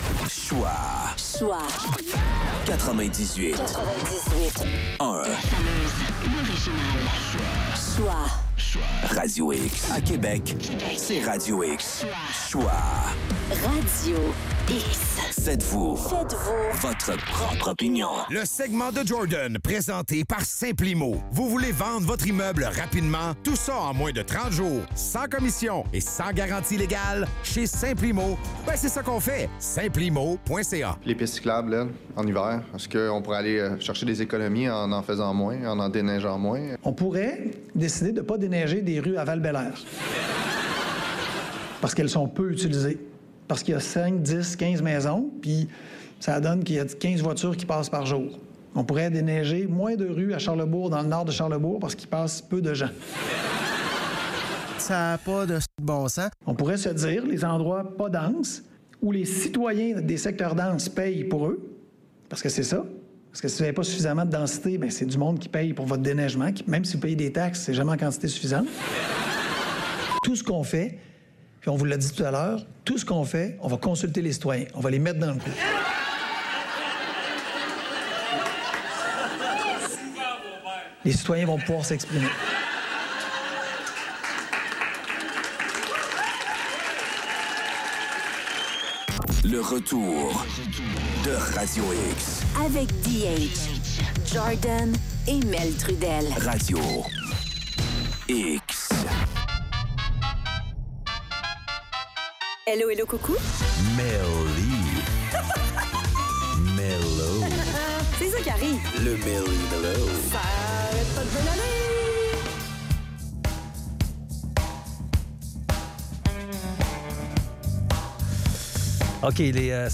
La chronique